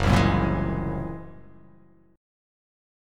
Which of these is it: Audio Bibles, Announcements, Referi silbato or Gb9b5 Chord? Gb9b5 Chord